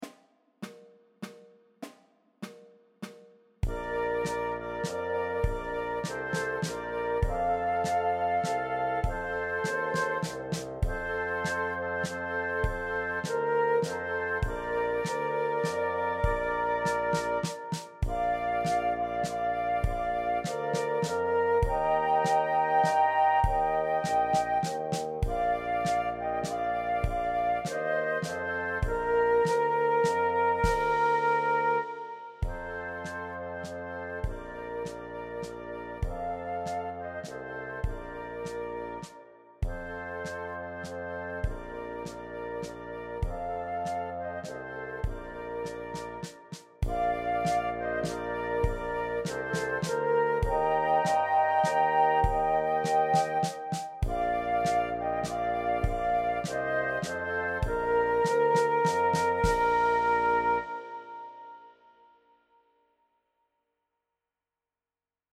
• 16 sehr leichte, dreistimmige Weihnachtslieder